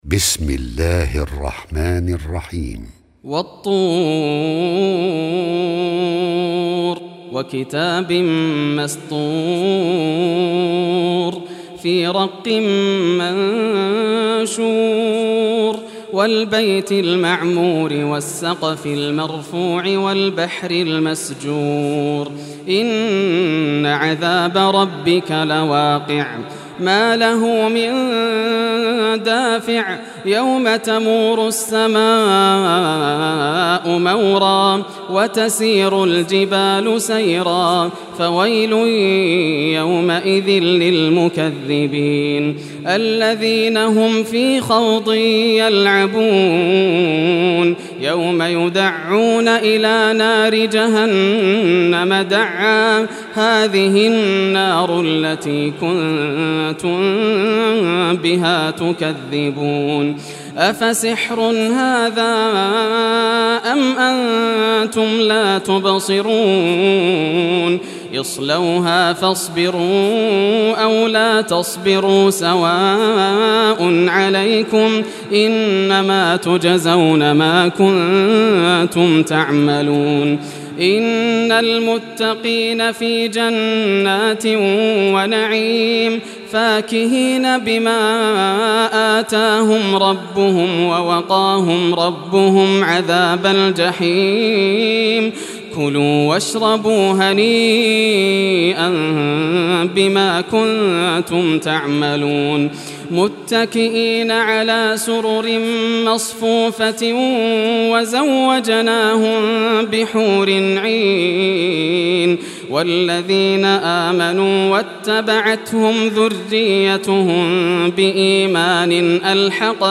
Surah At-Tur Recitation by Yasser al Dosari
Surah At-Tur, listen or play online mp3 tilawat / recitation in Arabic in the beautiful voice of Sheikh Yasser al Dosari.